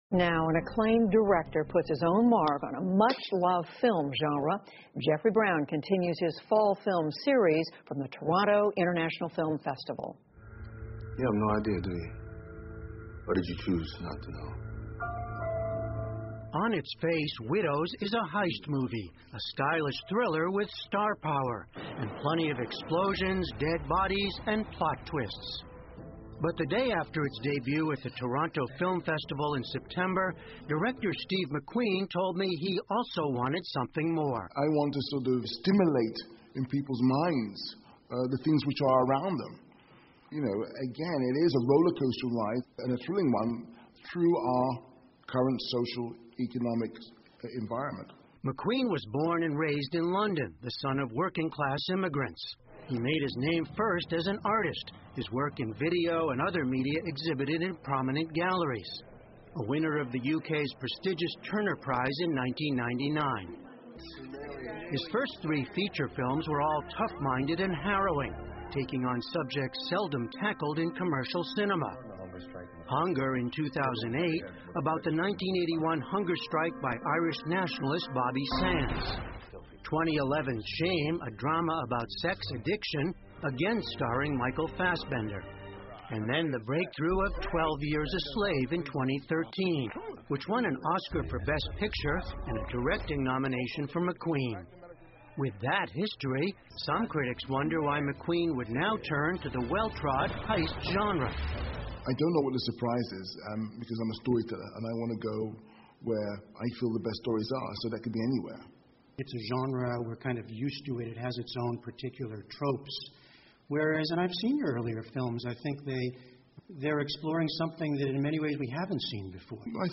PBS高端访谈:了解一下电影《寡妇特工》 听力文件下载—在线英语听力室